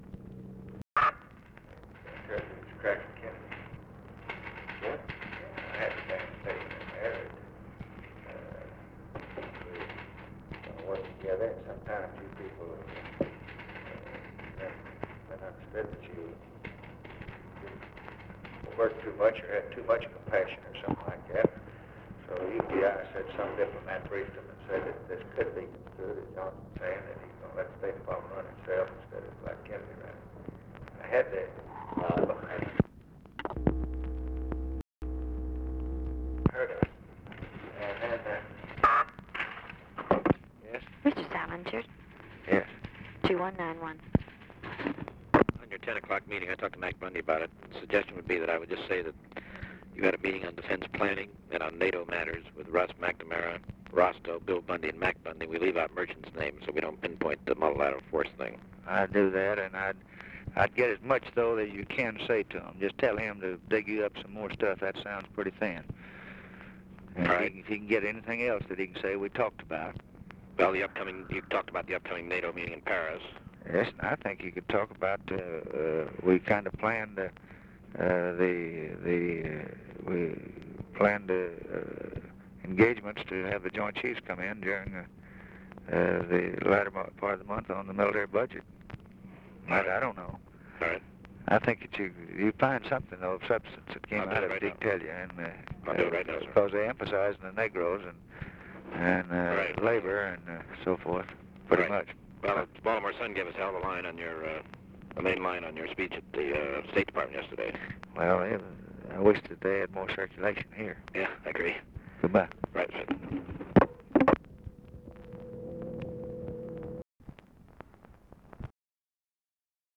OFFICE CONVERSATION, December 06, 1963
Secret White House Tapes | Lyndon B. Johnson Presidency